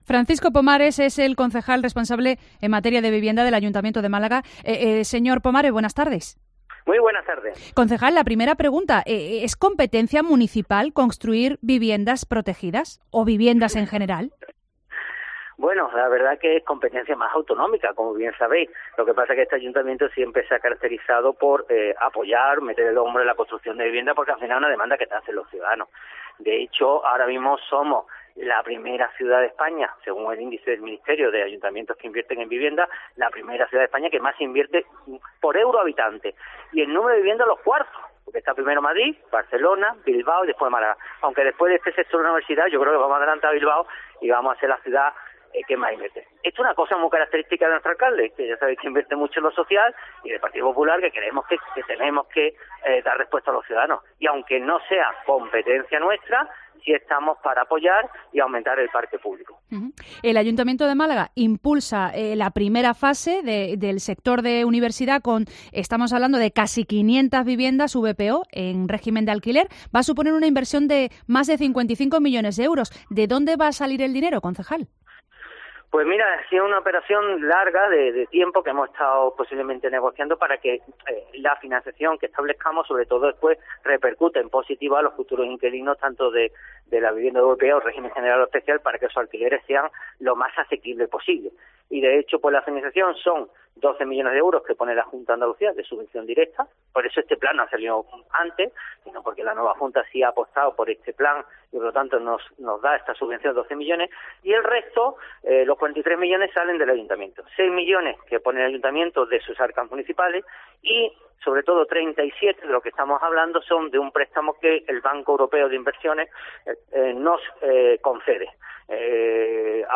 Entrevista con Francisco Pomares concejal responsable de Vivienda